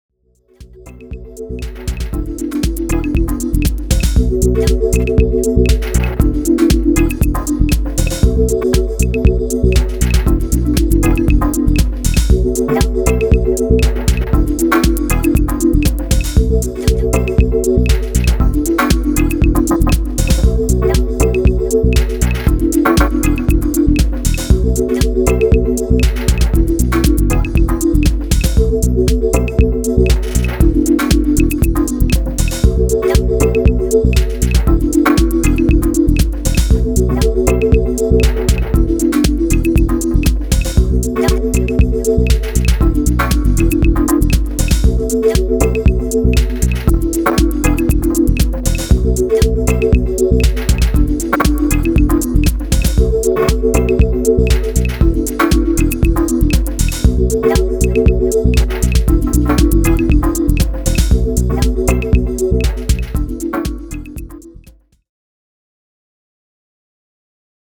I’ve tried to learn the whole Elektron way where a simple loop evolves abit through modulation and trig conditions.
I also followed some of these steps you wrote out to get chopped samples in this… thing. which I had a lot of fun doing.